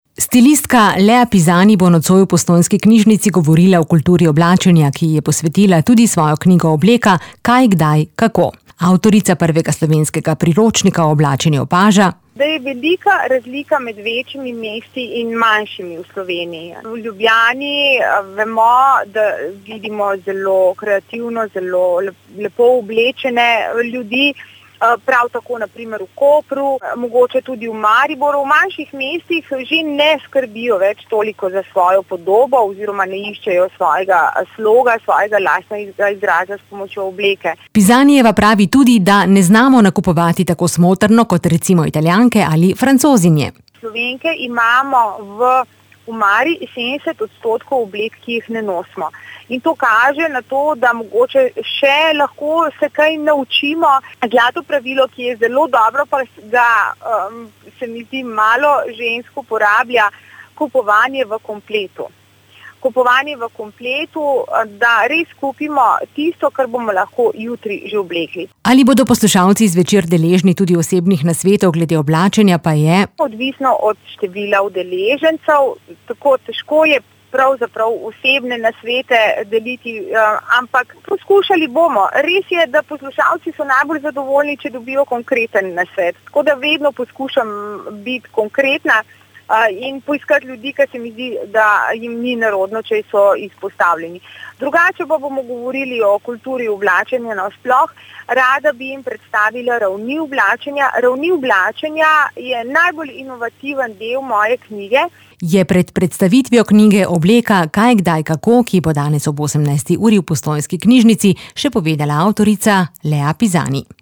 V sredo so v ZD Postojna pripravili razstavo ter brezplačno merjenje krvnega sladkorja in tlaka. Prisluhni pogovoru